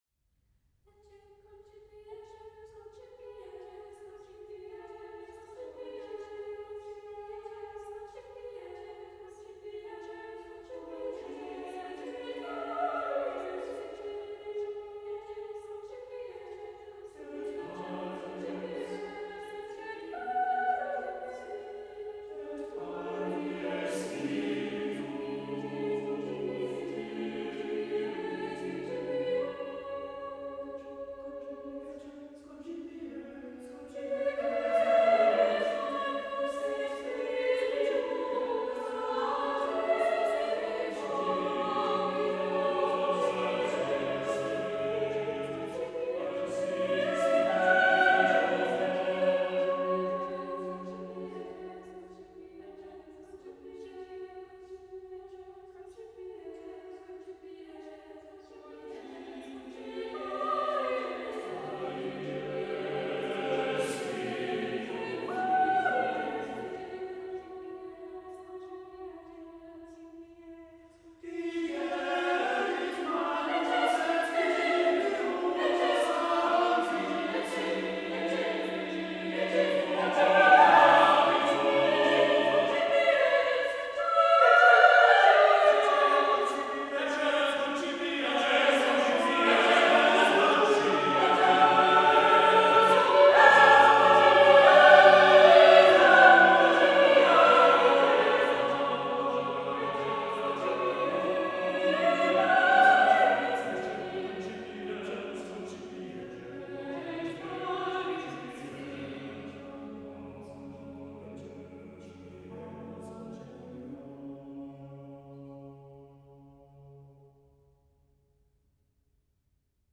Voicing: SSAATTBB